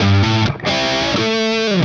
Index of /musicradar/80s-heat-samples/130bpm
AM_HeroGuitar_130-A02.wav